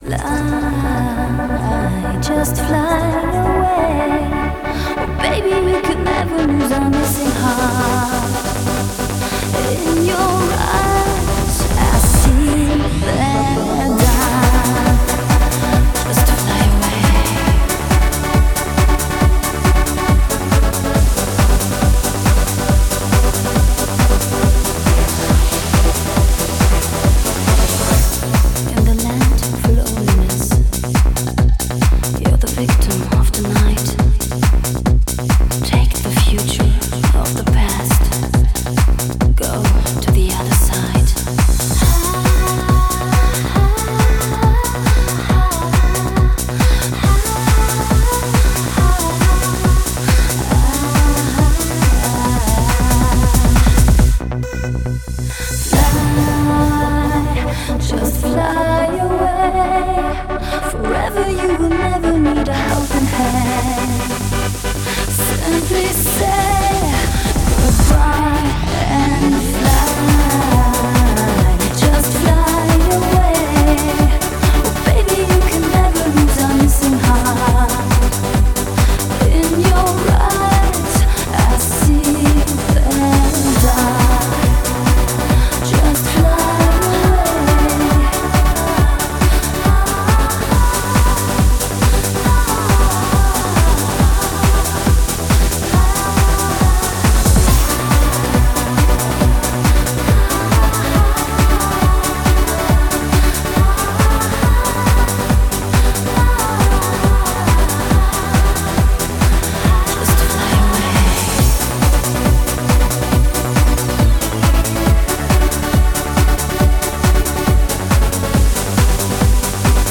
BPM138--1